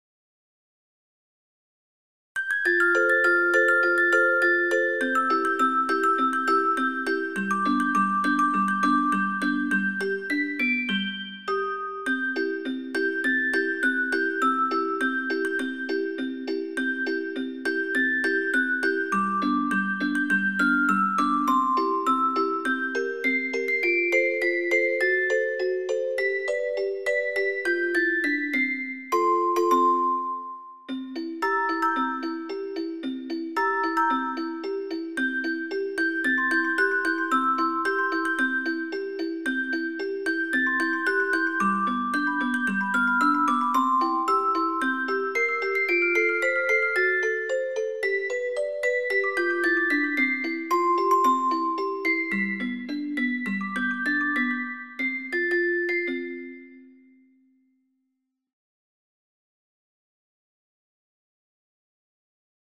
MP3オルゴール音楽素材
オルゴール チェレスタ ミュージックボックス